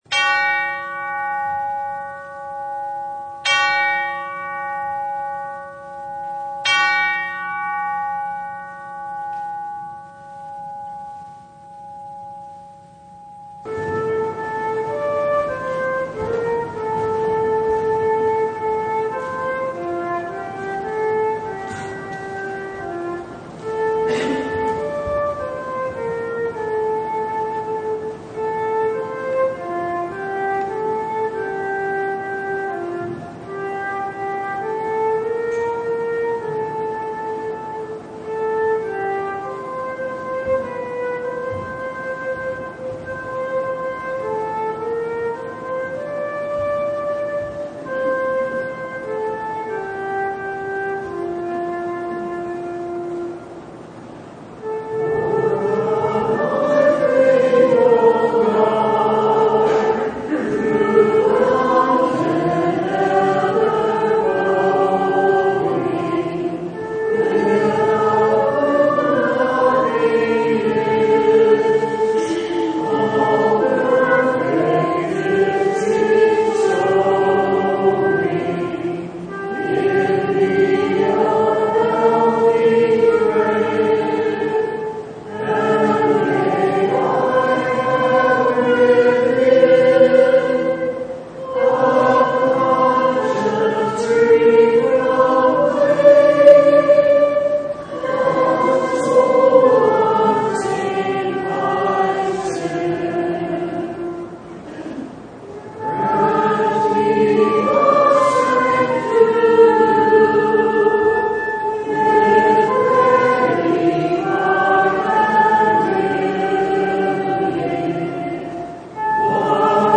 Bible Text: Matthew 13:44–52 | Preacher: visiting pastor
Service Type: Sunday